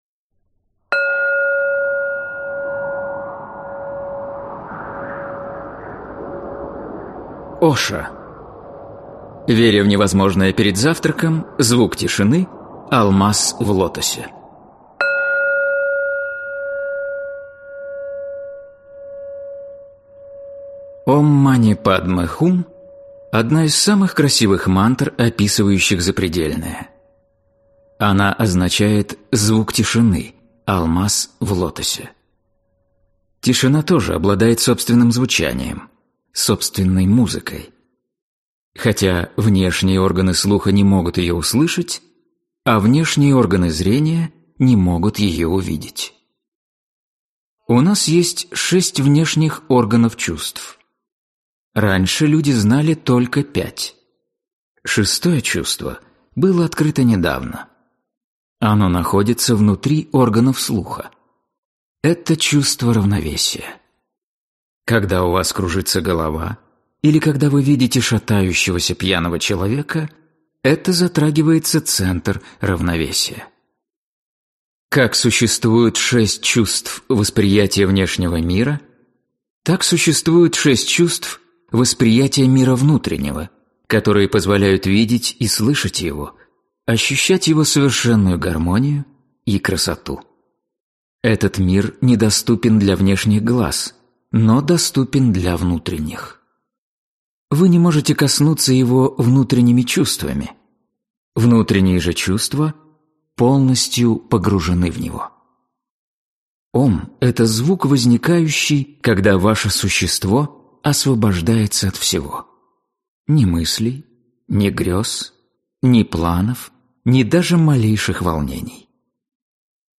Аудиокнига Веря в невозможное перед завтраком. Звук тишины, алмаз в лотосе | Библиотека аудиокниг